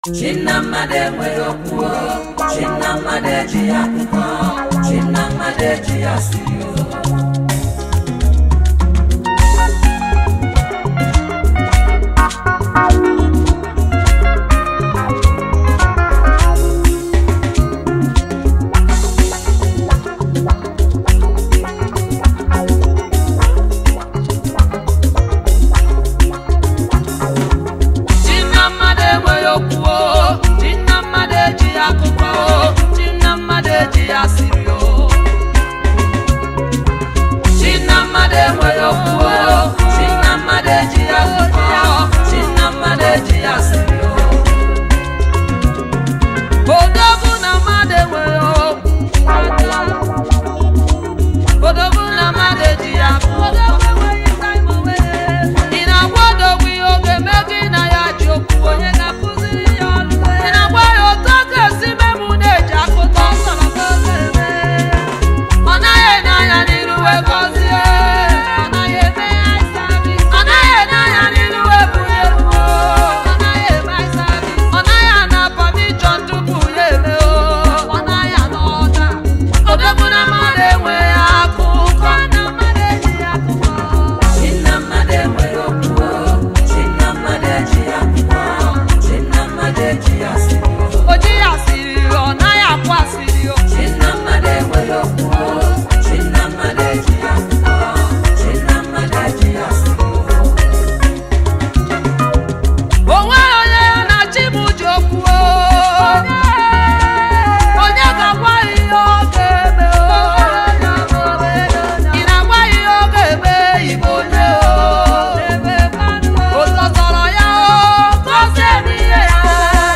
has released a Lively worship.
February 14, 2025 Publisher 01 Gospel 0